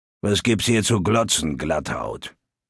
Fallout 3: Audiodialoge
Malegenericghoul_dialoguemsmini_greeting_000c9cd4.ogg